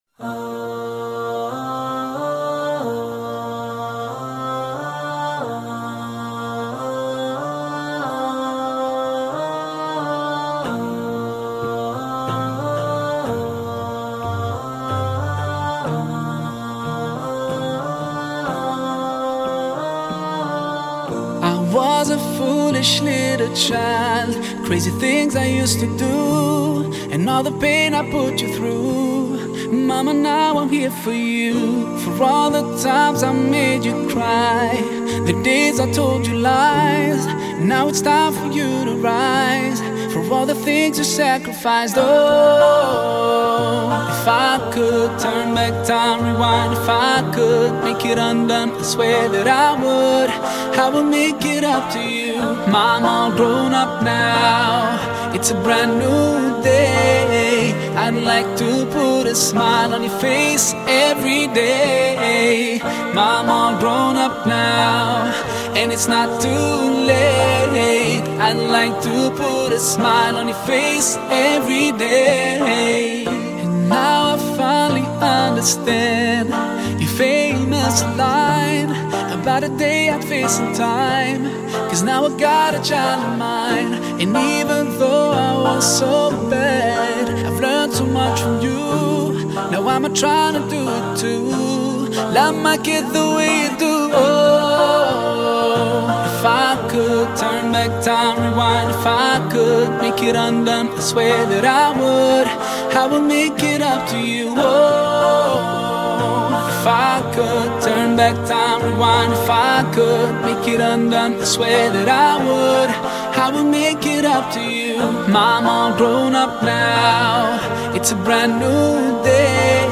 دسته : موسیقی ملل